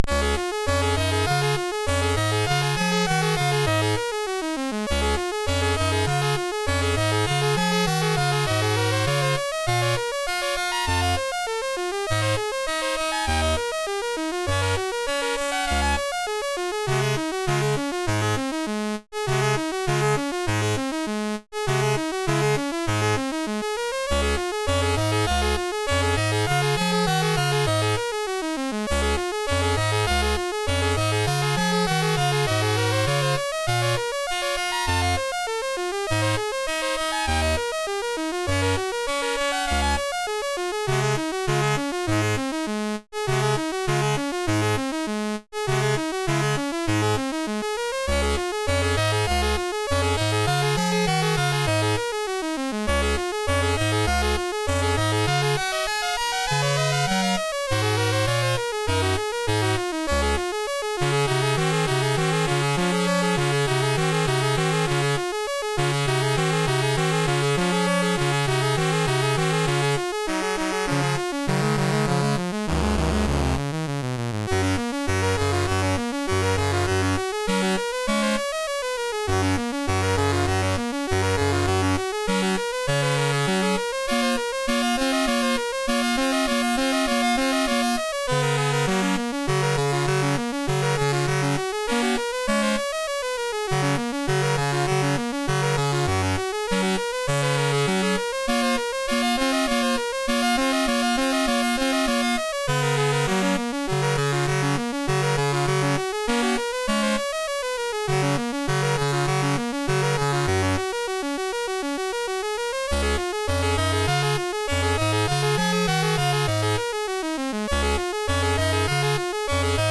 Commodore SID Music File
fanfare_ 1.mp3